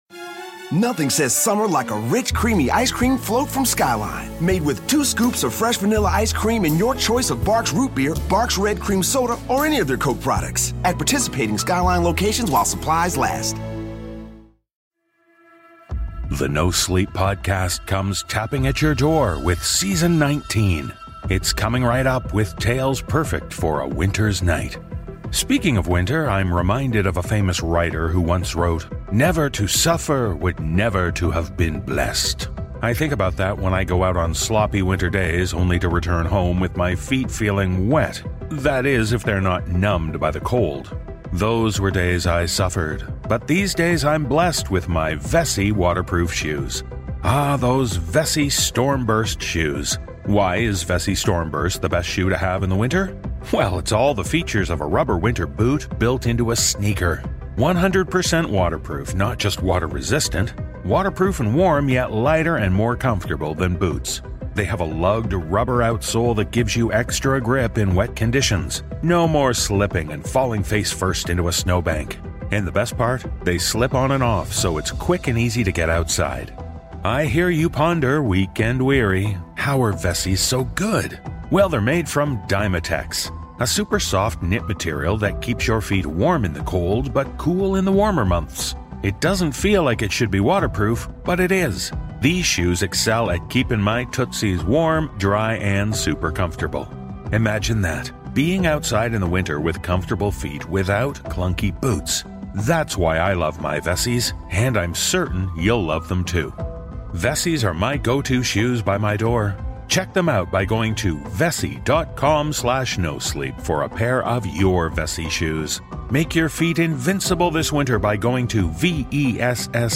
Cast: Narrator